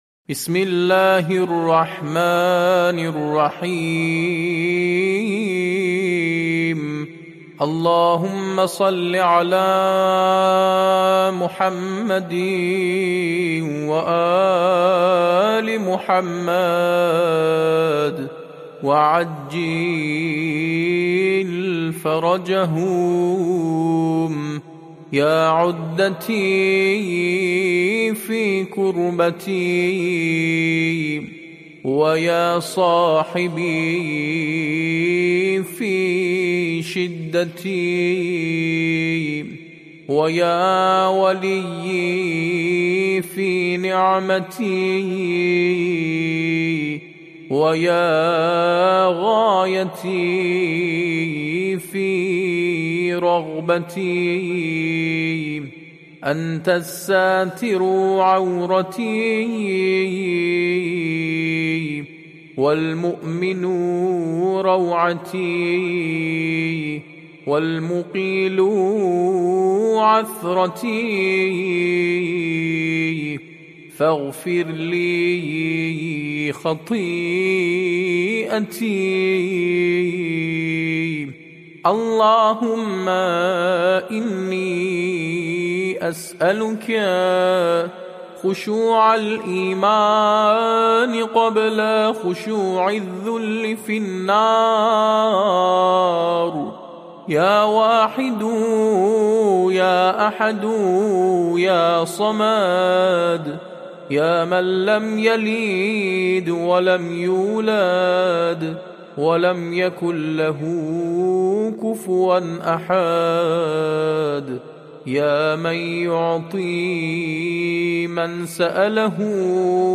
دعاء یاعدتی